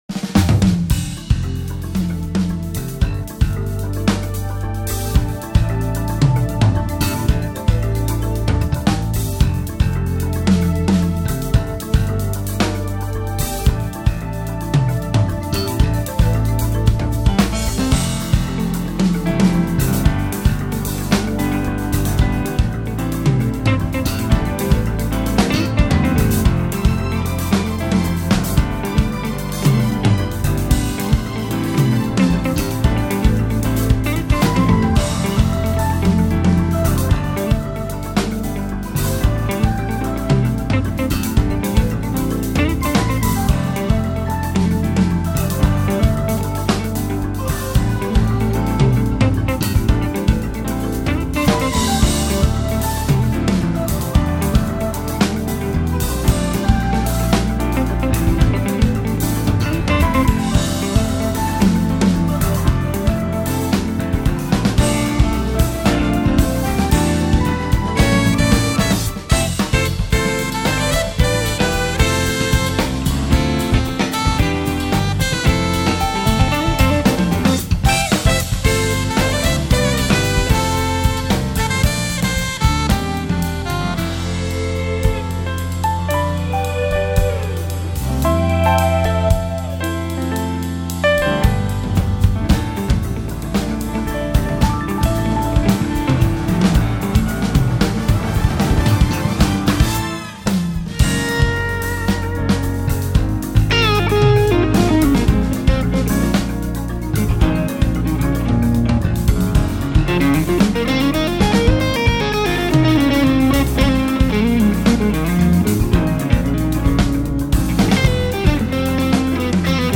year-end special live
2016-02-06 청량하고 아름답네요.